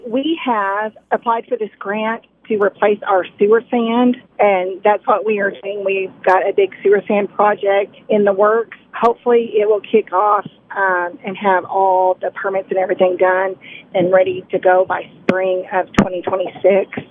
Harrison describes the reason they applied for the grant.